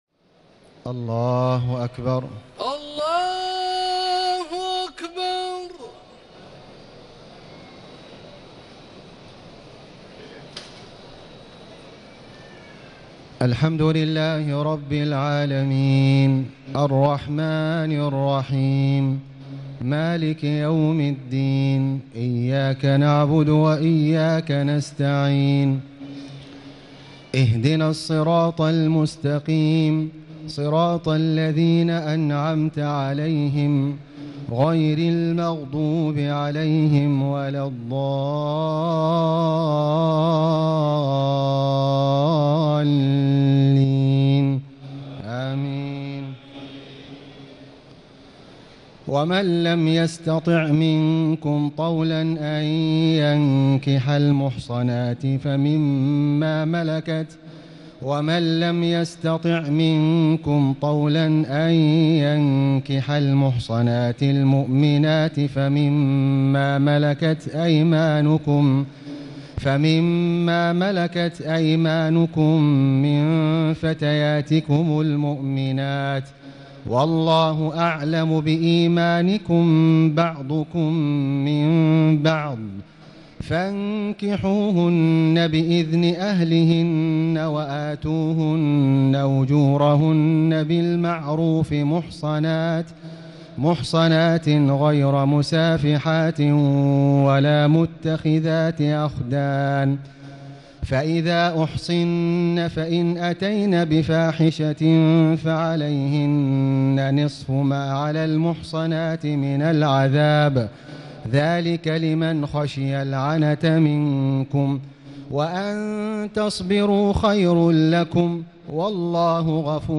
تهجد ليلة 25 رمضان 1439هـ من سورة النساء (25-99) Tahajjud 25 st night Ramadan 1439H from Surah An-Nisaa > تراويح الحرم المكي عام 1439 🕋 > التراويح - تلاوات الحرمين